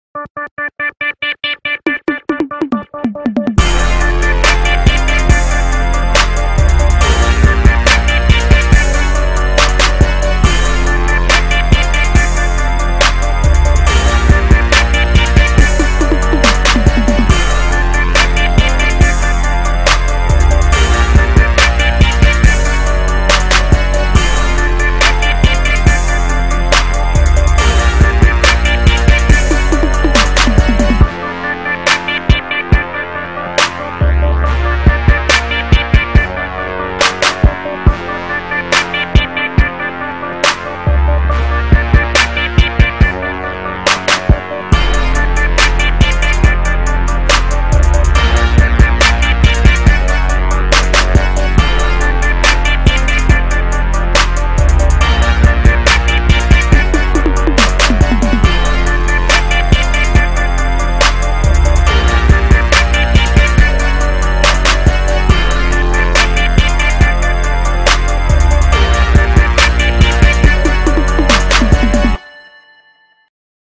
Some of the most classic sounds you will ever find included. Anywhere from brass to synths or even trap-whistles, these sounds will perfectly mix in with their hard-knockin drums!